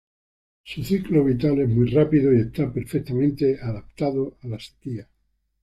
se‧quí‧a
/seˈkia/